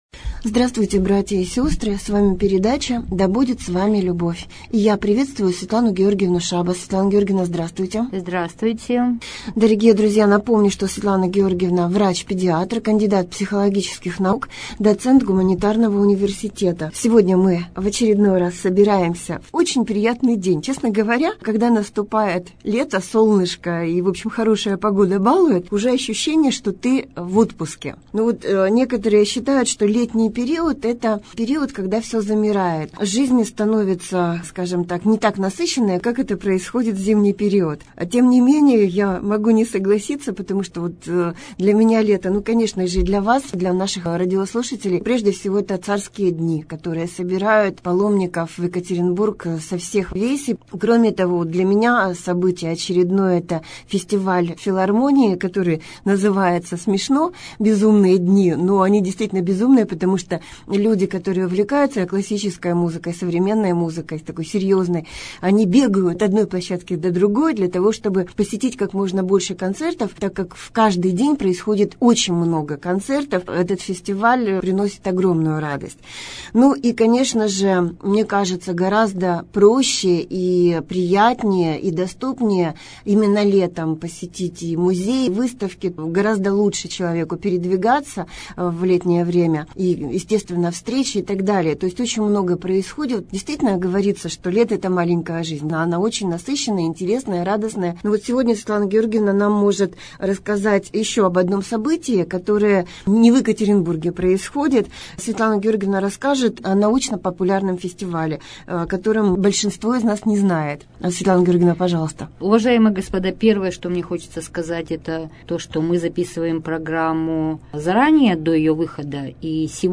Прямой эфир от 26.08.19